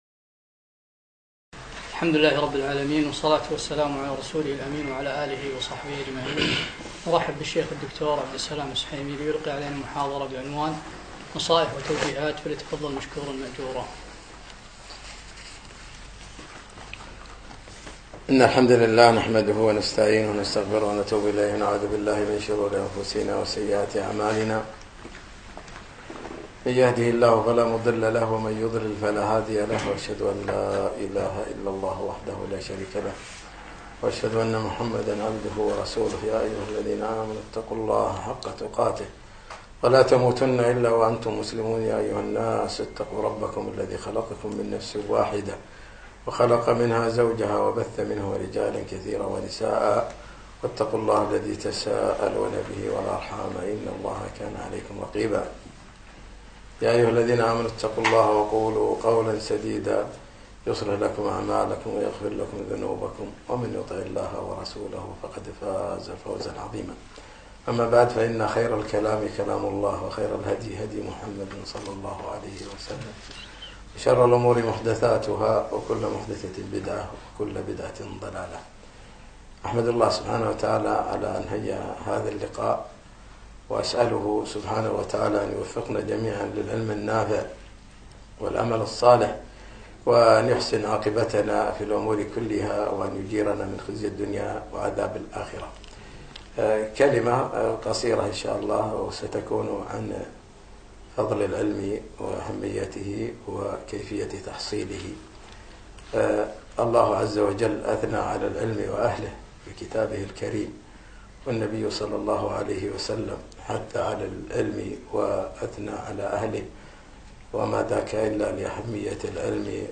في ديوان مشروع الدين الخالص الكويت